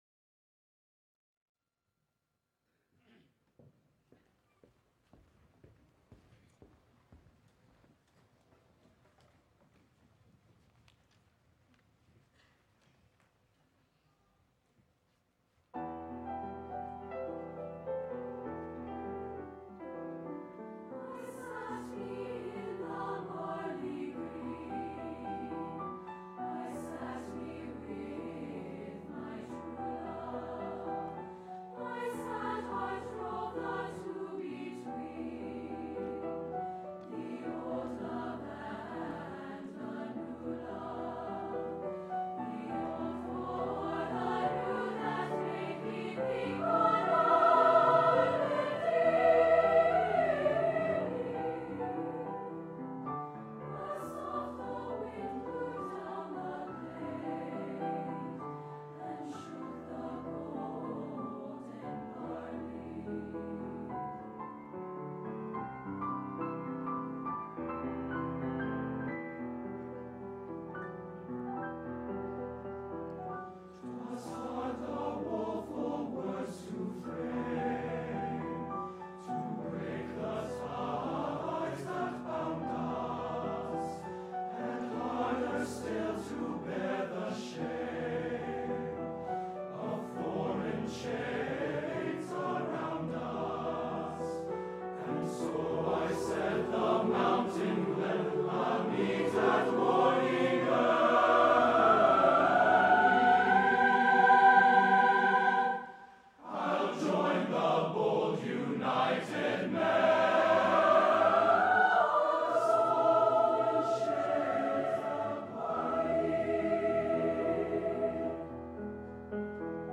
Composer: Traditional Irish
Voicing: SATB